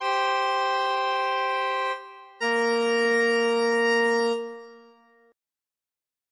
Nosso segundo trecho começa com uma idéia tão comum quanto a primeira: a progressão contrapontística entre dois pontos distintos.
Nossa intenção é a de ir do para o sib na voz superior e do sol para o sib na voz inferior.
Uma das muitas soluções possíveis, talvez a mais óbvia, é a de preencher o espaço por graus conjuntos.